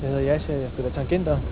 Click on the picture to hear Jascha's voice...